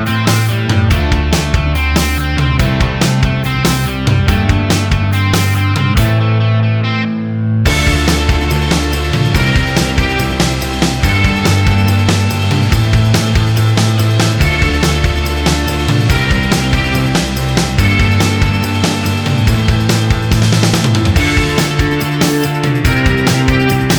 Minus Main Guitar Indie / Alternative 3:15 Buy £1.50